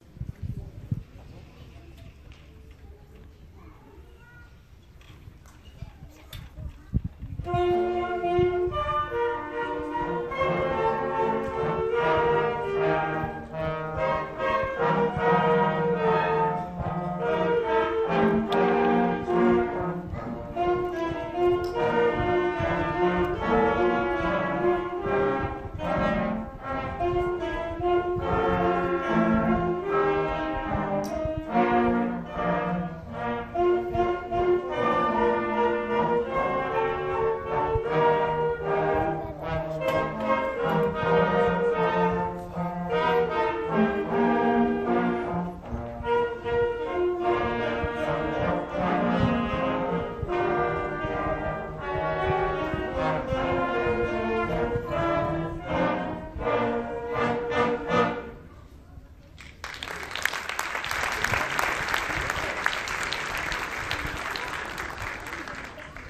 Ecole Elémentaire Publique Pierre de Ronsard de Poissy Concert de la classe orchestre
Après une matinée de répétitions, nos musiciens ont joué les morceaux travaillés chaque semaine devant un public conquis!